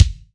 MH AMKICK4EQ.WAV